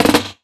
150JAMROLL-L.wav